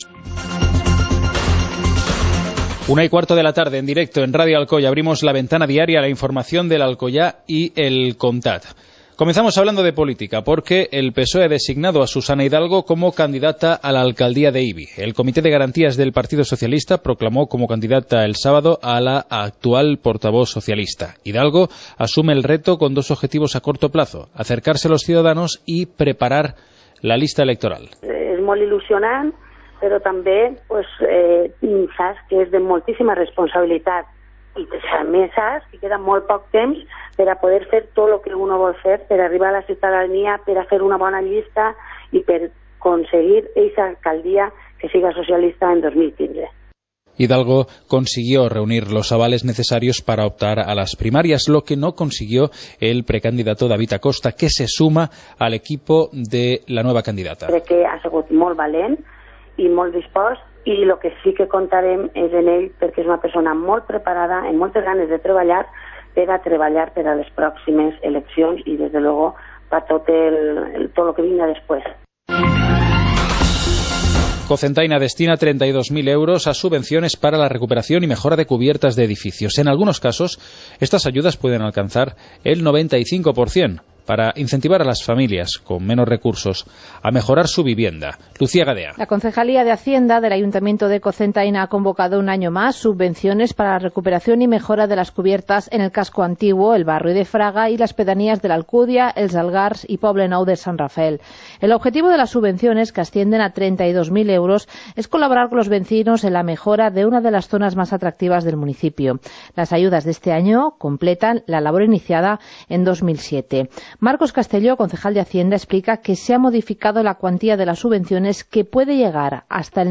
Informativo comarcal - lunes, 13 de octubre de 2014